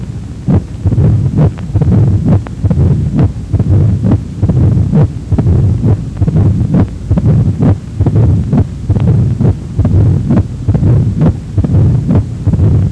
Mid-diastolic murmur  พบในภาวะที่มีการตีบ (stenosis) ของลิ้น atrioventricular
เช่นฟังได้ในผู้ป่วยที่ VSD หรือ ASD ขนาดใหญ่ มีลักษณะเป็น decresendo-cresendo
และมีเสียง S1 ดังขึ้น